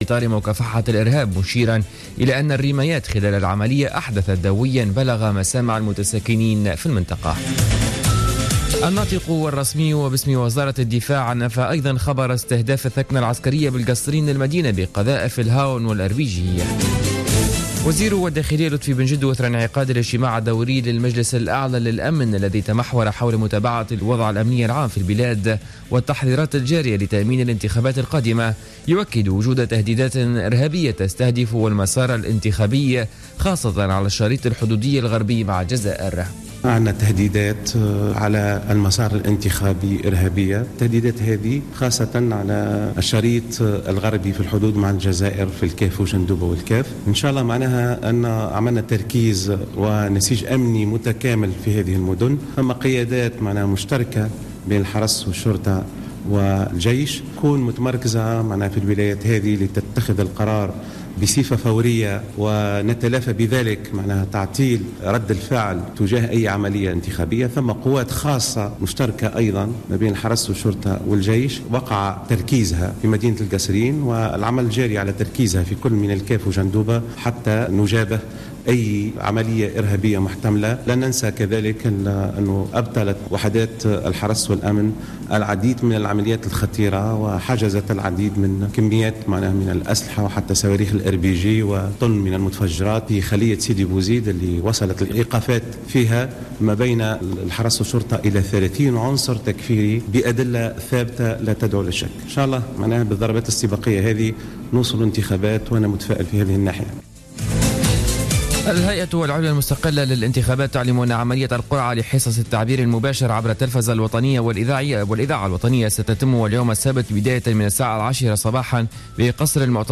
نشرة أخبار السابعة صباحا ليوم السبت 13-09-14